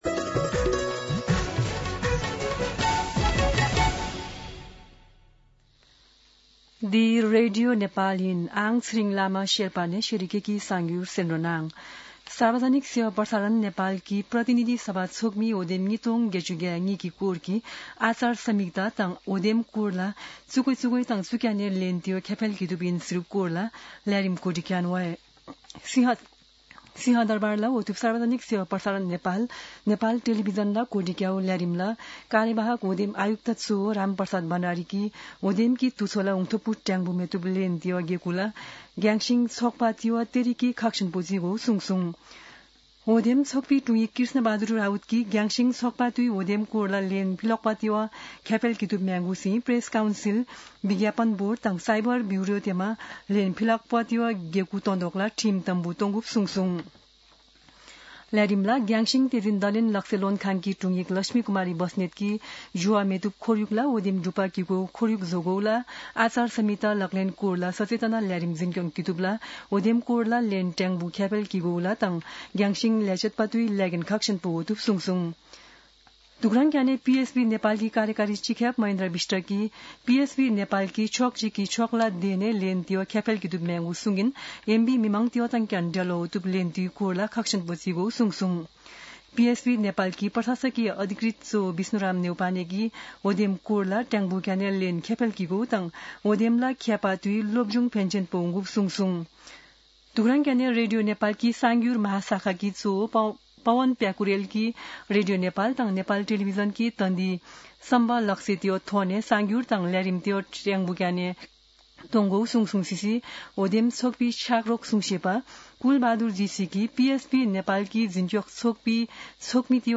शेर्पा भाषाको समाचार : १२ माघ , २०८२
Sherpa-News-12.mp3